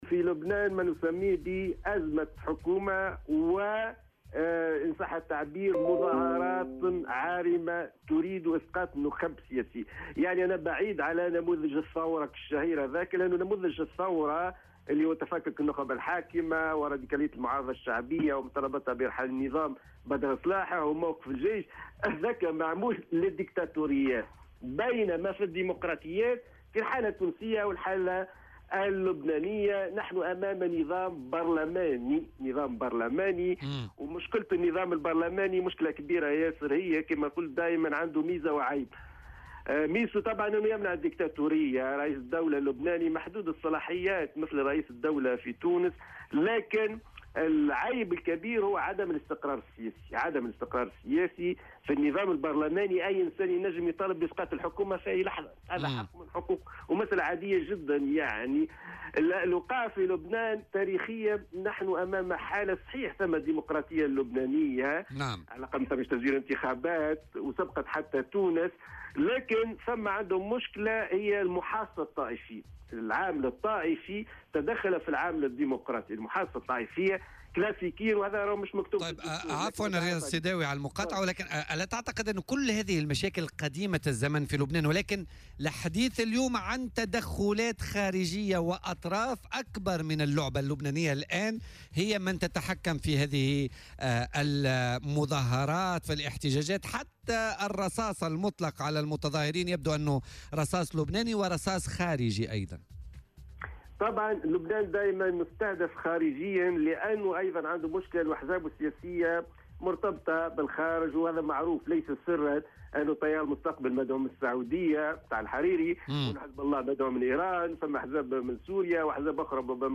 وأضاف في مداخلة له اليوم في برنامج "بوليتيكا" على "الجوهرة أف أم" أن هذه المظاهرات العارمة الغاية منها إسقاط نخب سياسية، وهو أمر بعيد عن نموذج الثورة، وفق تعبيره. وأوضح أن النظام البرلماني له مميزاته، لكن عيبه الكبير هو عدم الاستقرار السياسي، مشيرا إلى أن المحاصصة الطائفية هي التي زادت الوضع تعقيدا.